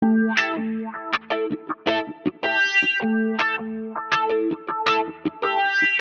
Tag: 80 bpm Dub Loops Guitar Electric Loops 1.01 MB wav Key : Unknown